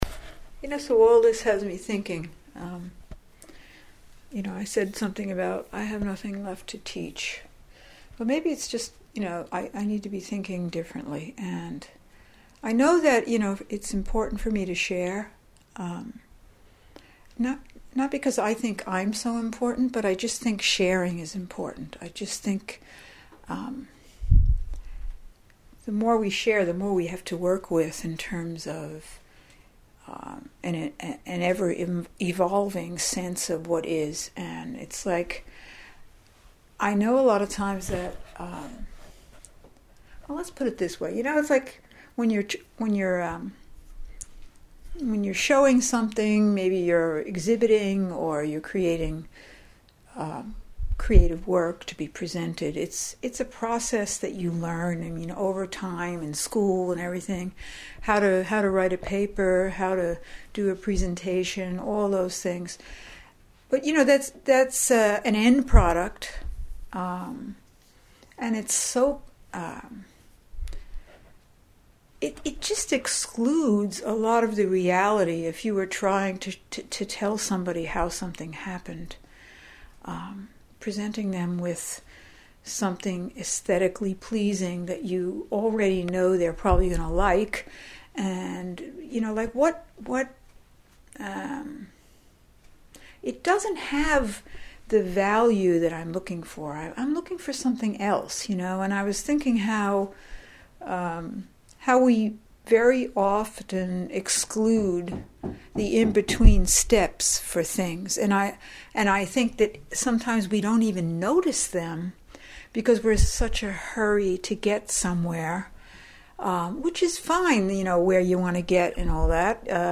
In listening to myself here (I never edit  or redo my audios)  I catch myself using the word "you" too often and remind myself to speak for myself and not others.
Love how you ended your last audio here; ” I’m really wandering, this is fun!” And that little laugh 😍🤩